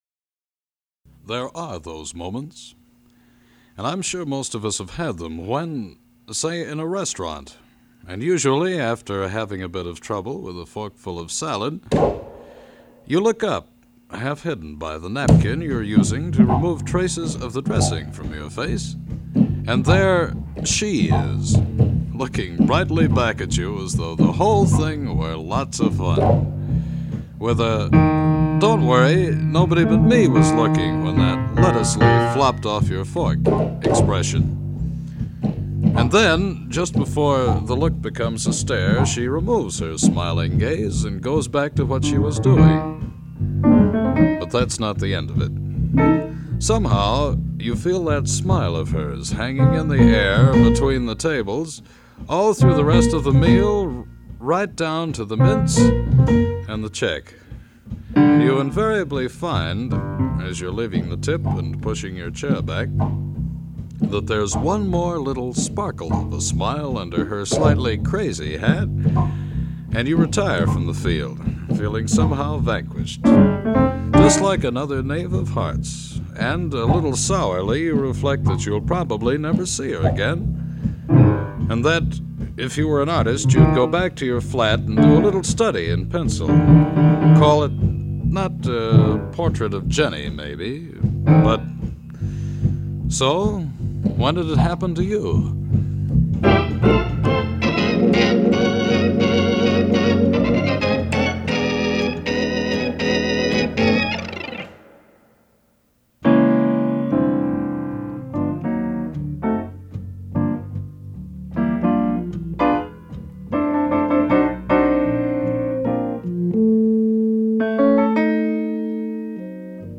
evocative writing style and commanding vocal gift